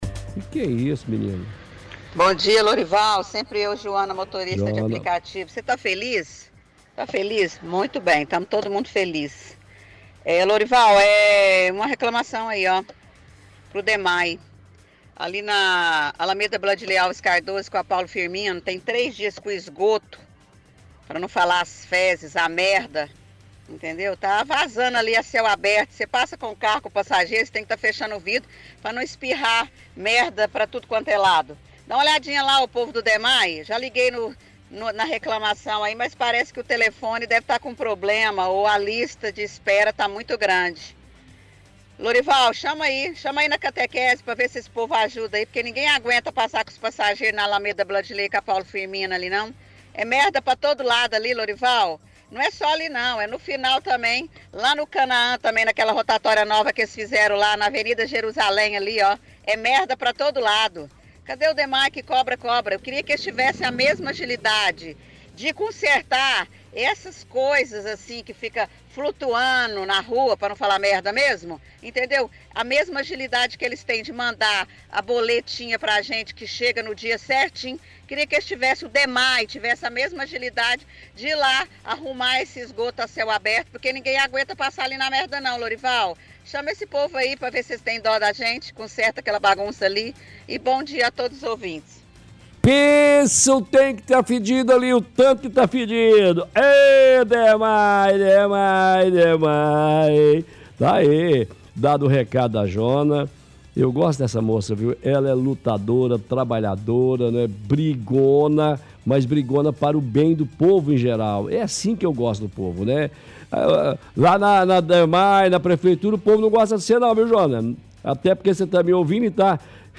– Ouvinte reclama que na Alameda Bladilei Alves Cardoso com Av. Paulo Firmino, fala tem esgoto vazando a céu aberto, questiona que já ligou no Dmae, mas não foi atendida.